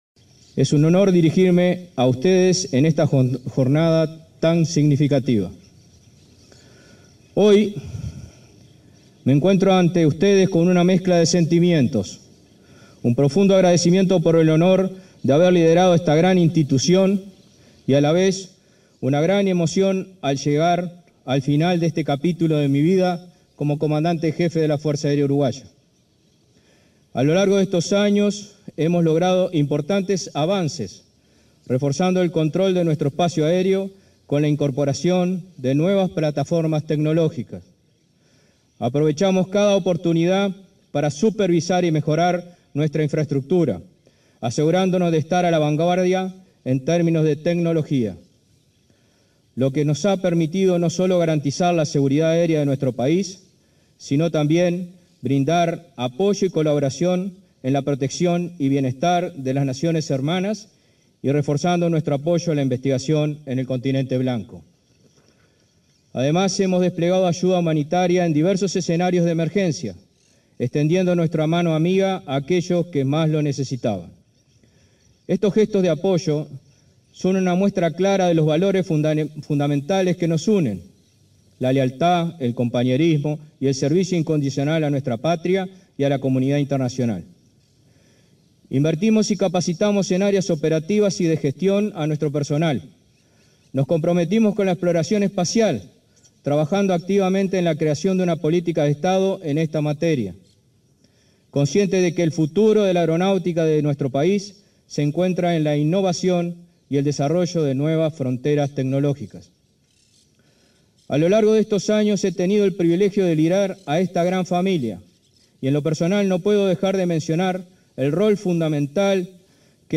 Ceremonia de asunción del nuevo comandante en jefe de la Fuerza Aérea Uruguaya
Ceremonia de asunción del nuevo comandante en jefe de la Fuerza Aérea Uruguaya 03/02/2025 Compartir Facebook X Copiar enlace WhatsApp LinkedIn En el marco de la ceremonia de asunción del nuevo comandante en jefe de la Fuerza Aérea, este 3 de febrero, se expresaron el entrante, Fernando Colina, y el saliente, Luis De León.